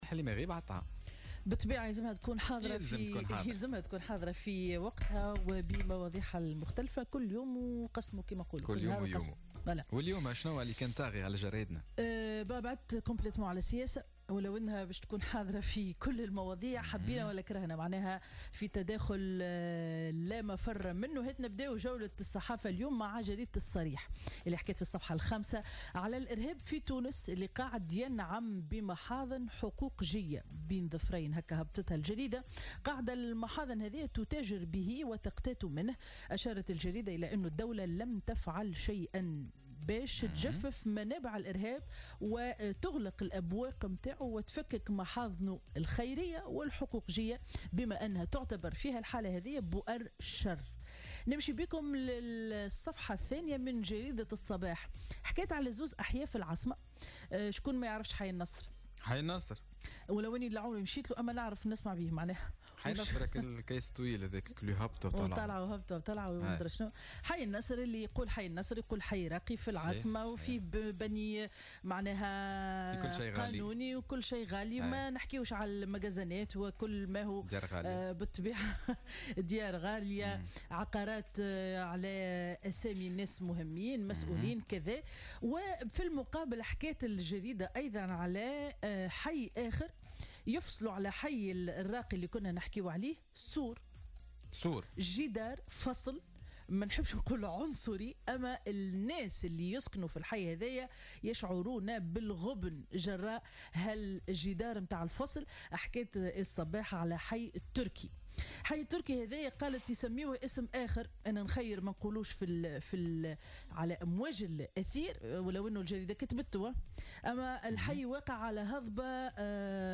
Revue de presse du vendredi 03 Novembre 2017